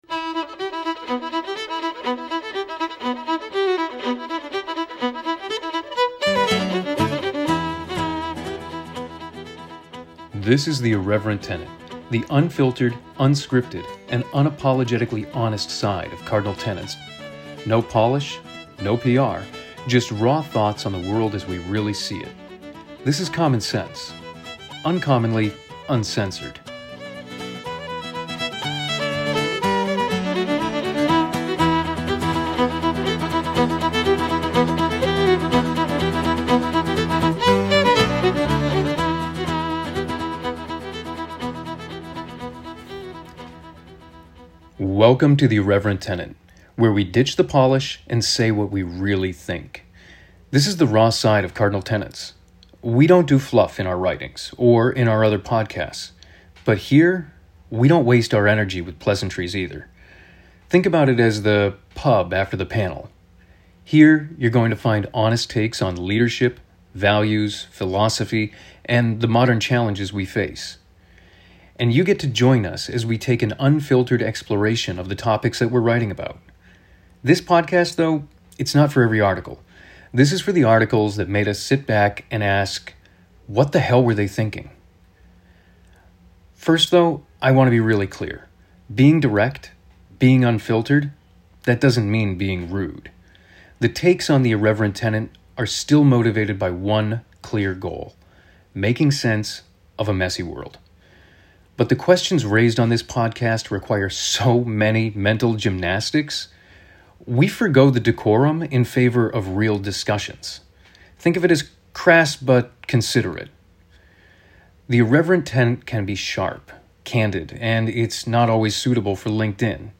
No polish. No prep. Just honest, unfiltered thinking.
It’s an unscripted take.
The unfiltered, often exasperated commentary that still leans on ethics and reason, but says it with a bit of grit, sarcasm, and probably a little swearing.
This podcast contains sarcasm, frustration, hard truths, and the occasional spicy word.